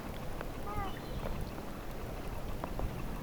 tuollainen lokin ääni, 3
tuollainen_lokin_aani_3.mp3